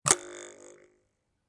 刀片振动和故障 " LFOKnive1
描述：记录的刀片声音
标签： 刀片声 点击 打击乐器 录音 毛刺 刀片 LFO 振动 现场录音 拍摄 声音
声道立体声